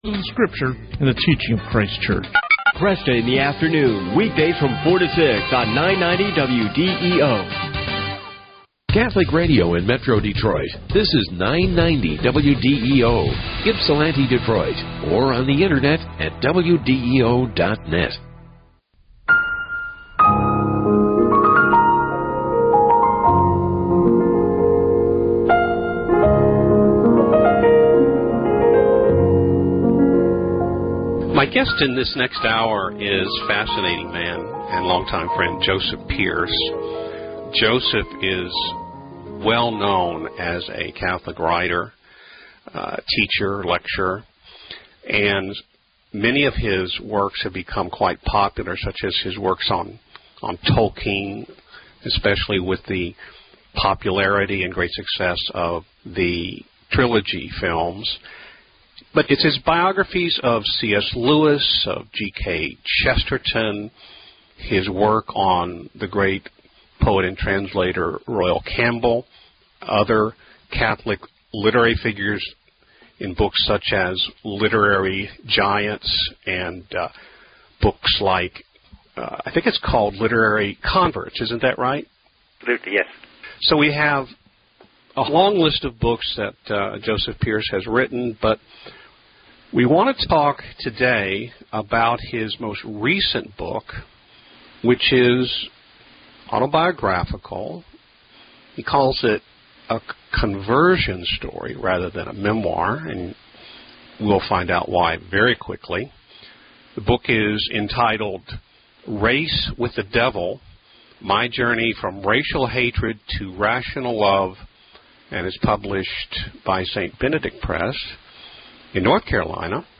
An ongoing archive of my publications and media interviews.